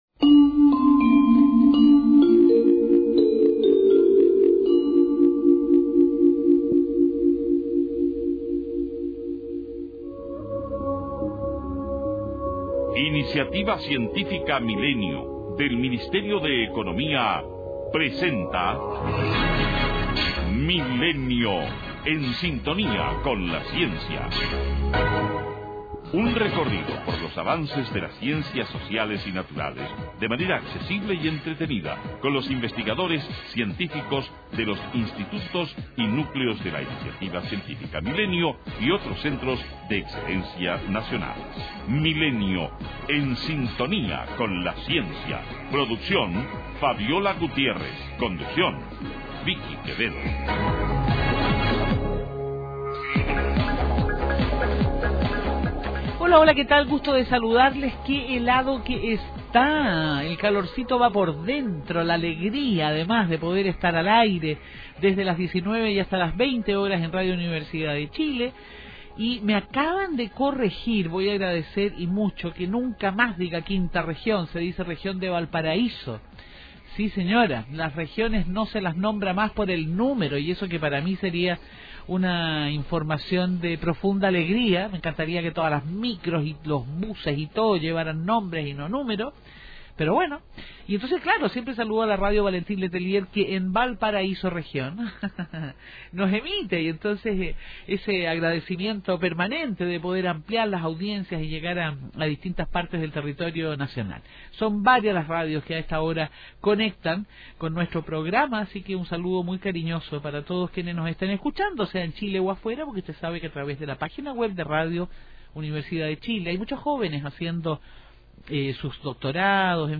Puedes escuchar la entrevista completa haciendo click en el siguiente enlace: Entrevista Juan Carlos Castilla Milenio en Sintonía con la Ciencia
Entrevista-Juan-Carlos-Castilla-Milenio-en-Sintonía-con-la-Ciencia.mp3